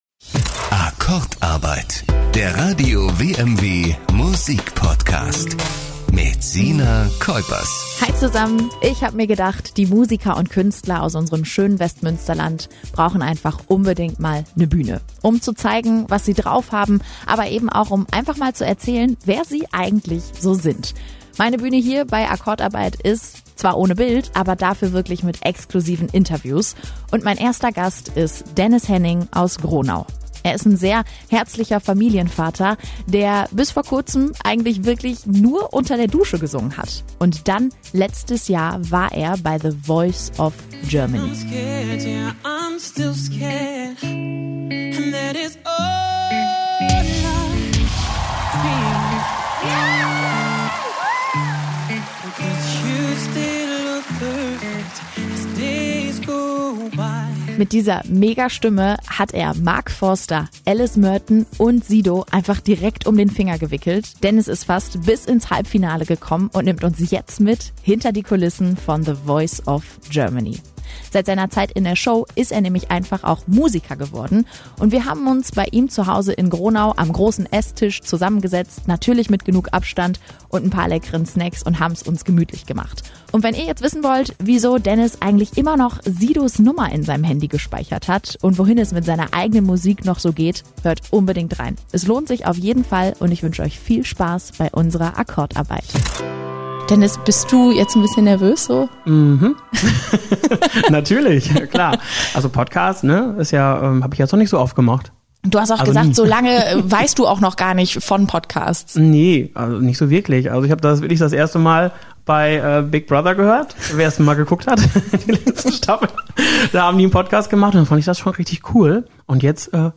Es wird gequatscht, gesungen und gelacht - Viel Spaß beim Reinhören!